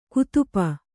♪ kutupa